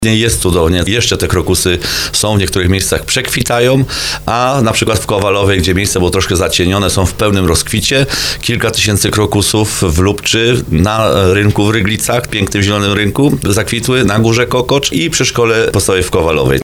Burmistrz Ryglic Paweł Augustyn, podkreśla, że krokusowe miejsca cieszą się dużym zainteresowaniem zarówno wśród lokalnej społeczności, jak i osób przyjezdnych.